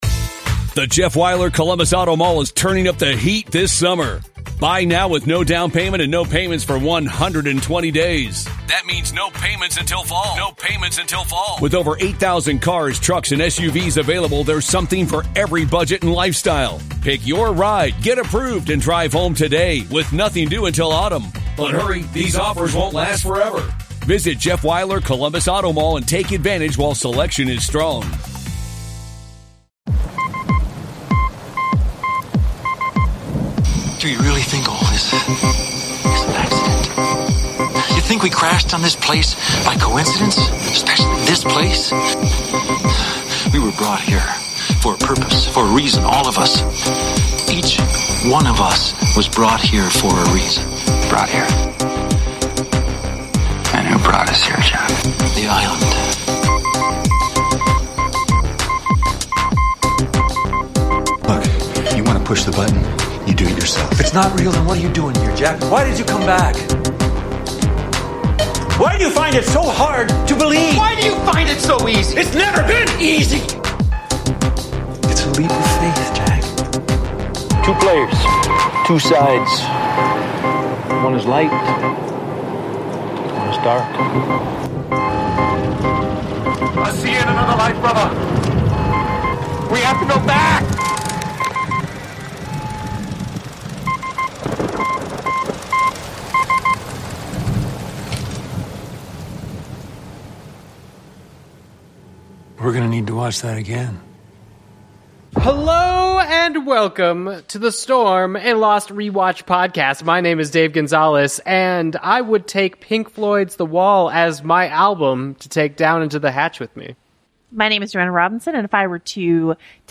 This week's podcast interview is with Henry Ian Cusick, who plays Desmond, the man in the hatch, and debuts his character on this episode of Lost.